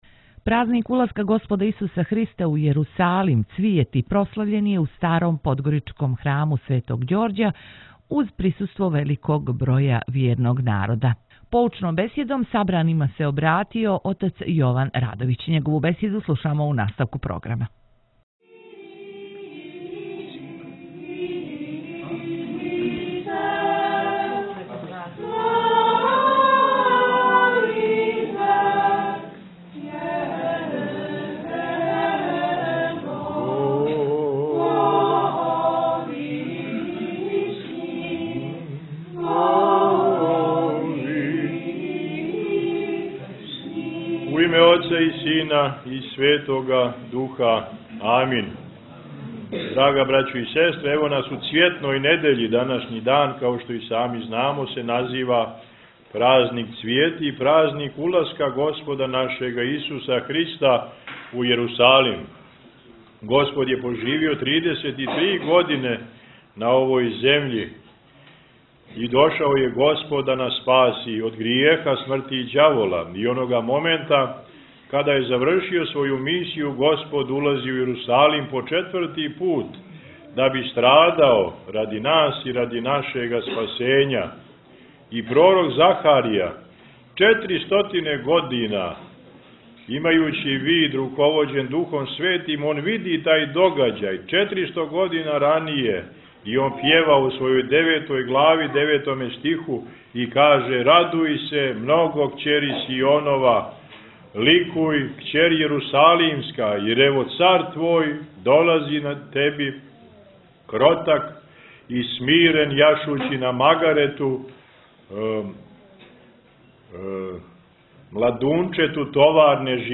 Након прочитаног јеванђелског зачала беседио је протојереј